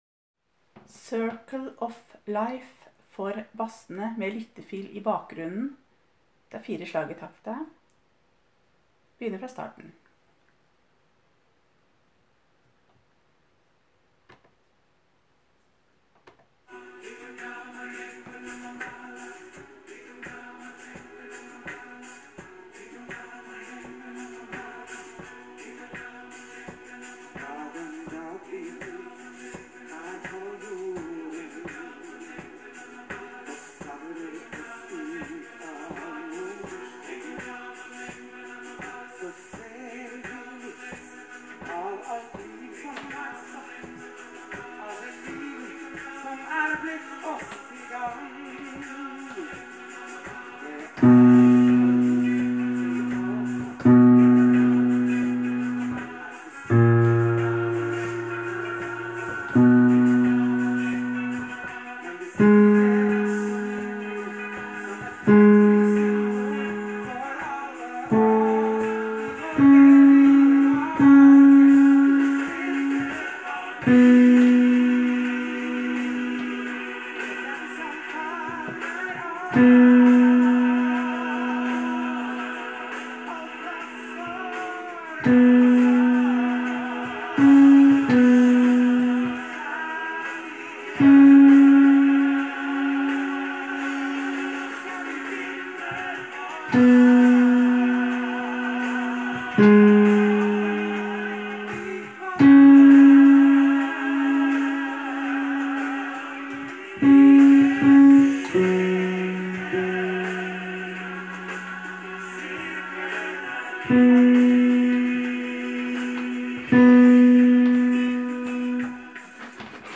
Jul 2017 Bass (begge konserter)
Circle of life med lyttefil i bakgrunnen:
Circle-of-life-Basser-Lyttefila-i-bakgrunn.m4a